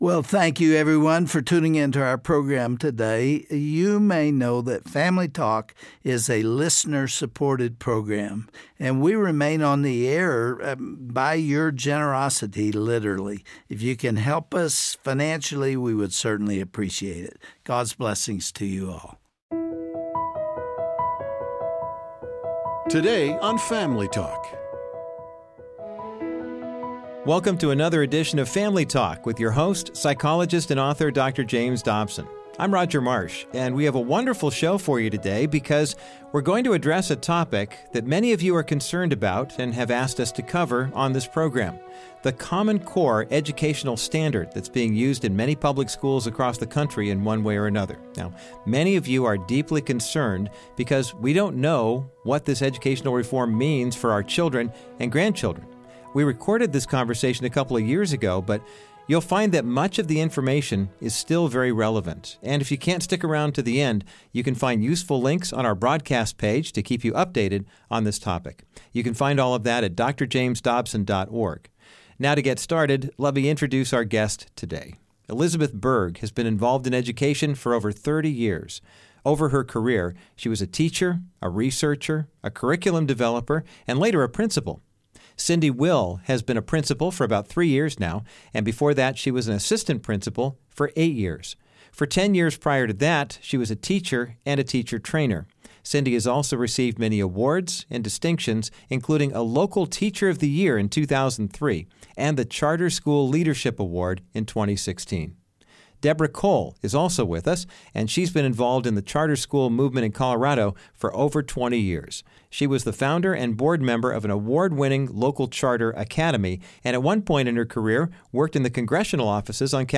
Isnt the government just trying to make sure our kids get a good education? On the next edition of Family Talk, Dr. James Dobson interviews a panel of administrators about the problem with common core.